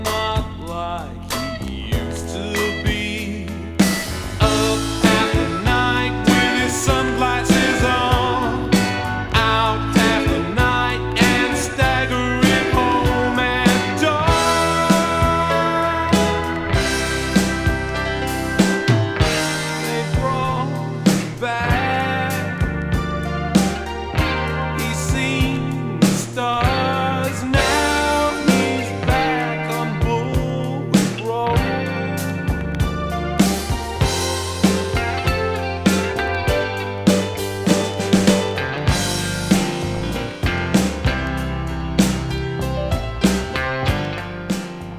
粒揃いに良い曲良いメロディが並び、コーラスワークも嬉しい良盤。
Rock, Pop, Indie　France　12inchレコード　33rpm　Stereo